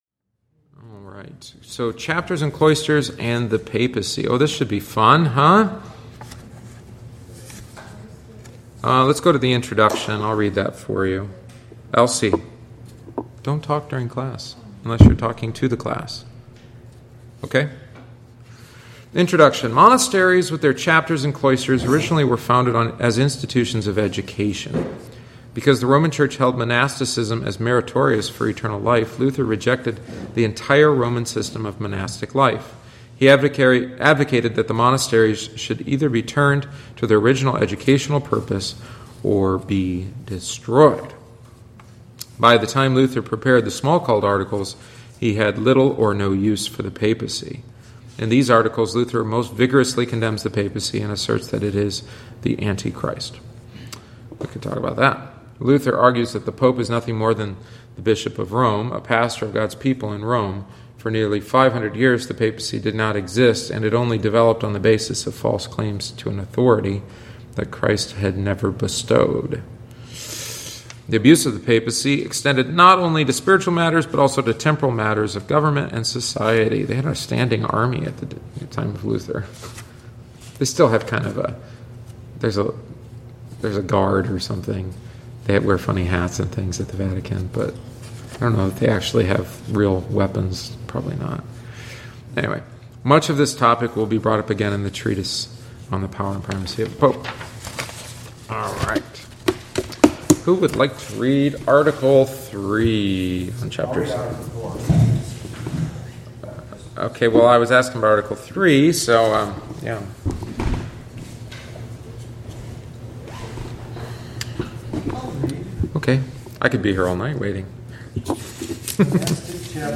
Join us each week on Wednesday after Divine Service (~7:45pm) for aÂ study of one of our Lutheran Confessions, the Smalcald Articles. Written by Martin Luther shortly before his death, it is vivid, to the point, and sometimes cantankerous.